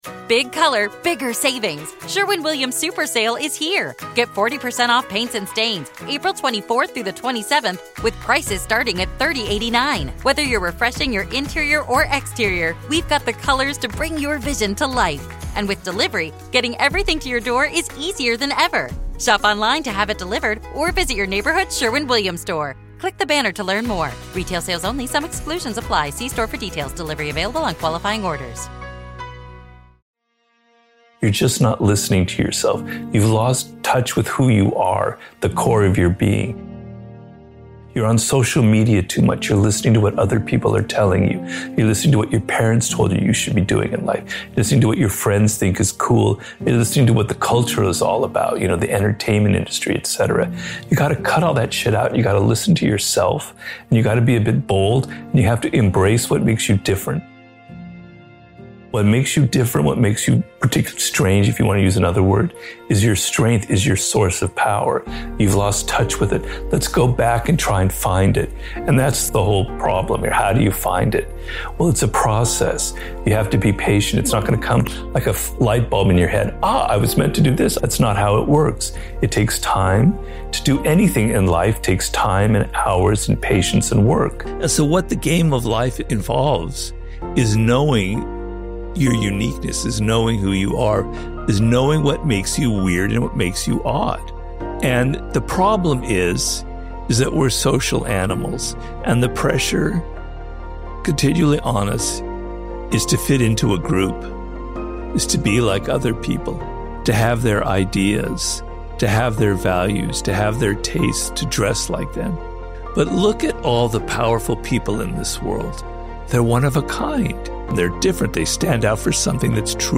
A powerful motivational speech by Robert Greene on the self mastery required to walk your own path. This is the time to leave the false path of seeking approval and return to your origins to discover your life's task. Learn to block out the noise and value yourself above the distractions of the crowd.